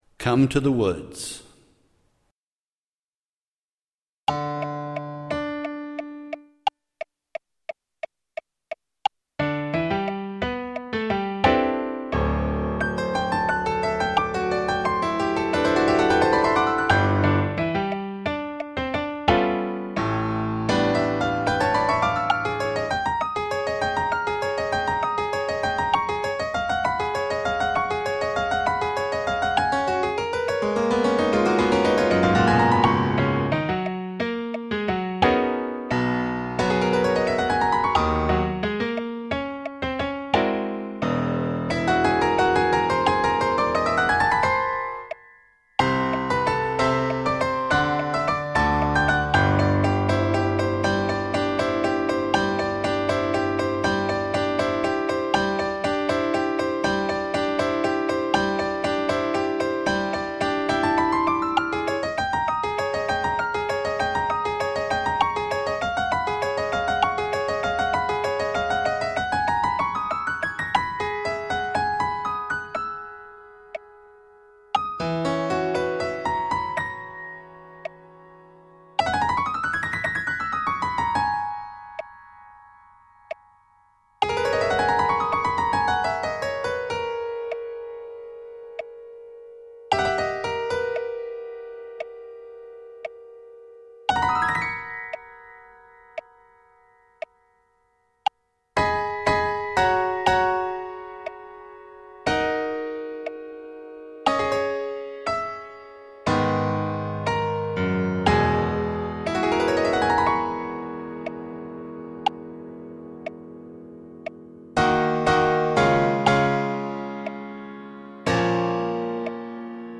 Piano   Come to the  +8 db.mp3